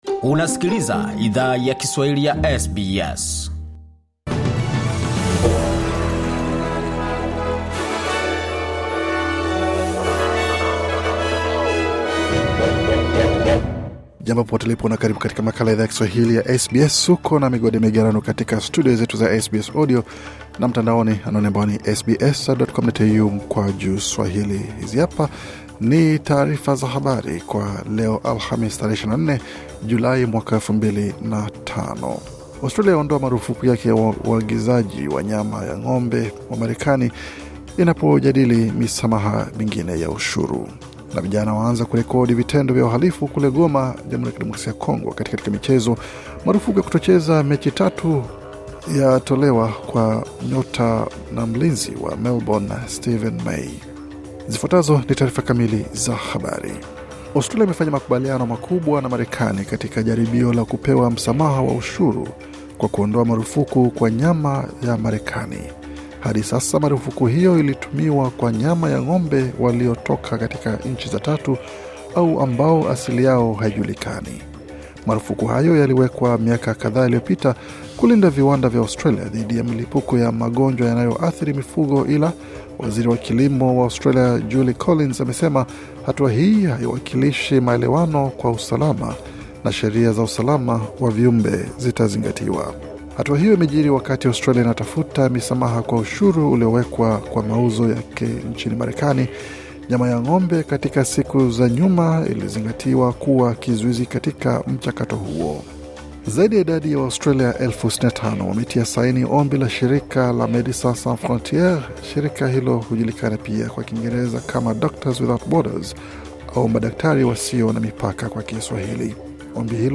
Taarifa ya Habari 24 Julai 2025